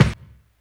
kick04.wav